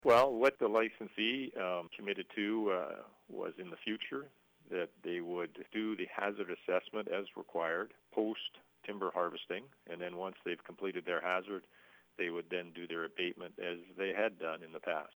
Forest Practices Board Chair Tim Ryan says it has since met BC standards.